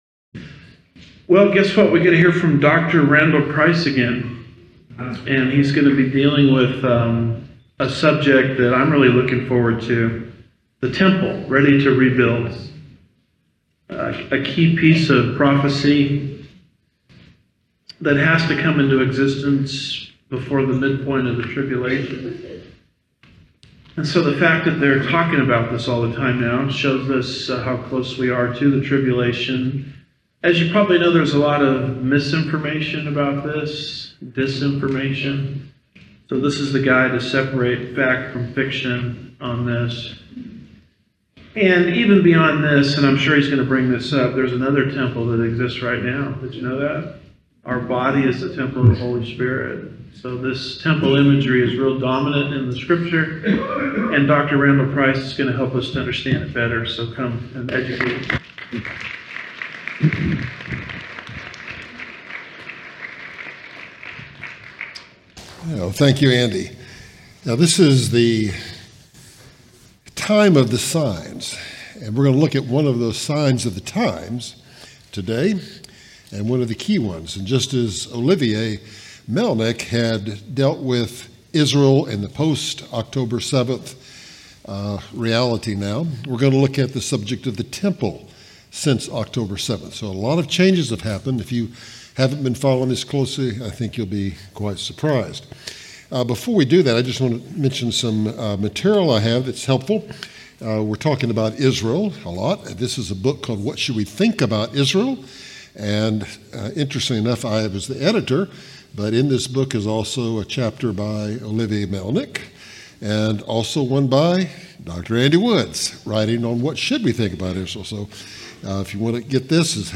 2025 Prophecy Conference